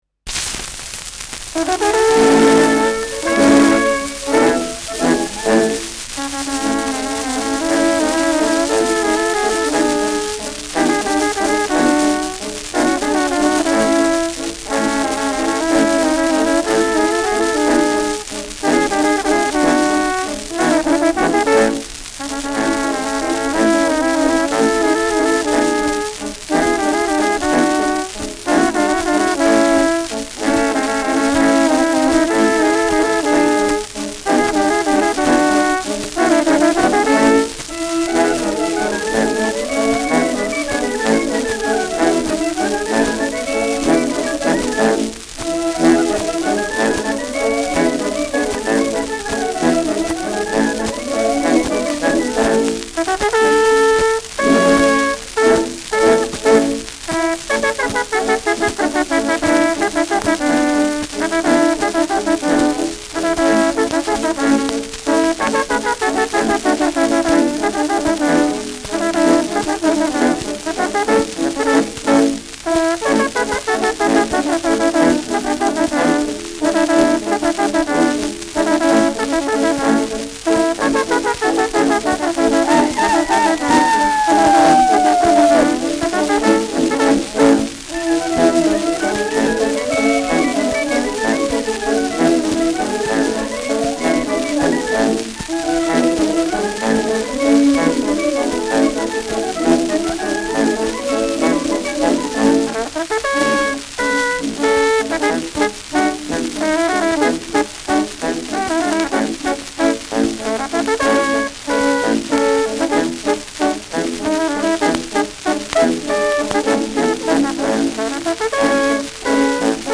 Schellackplatte
Mit Juchzer.